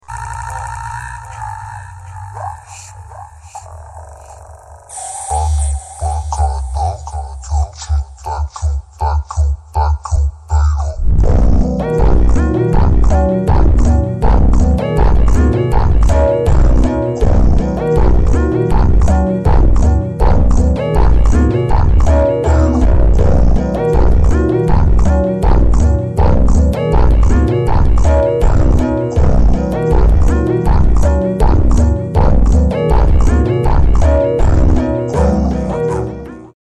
(Ultra Slowed)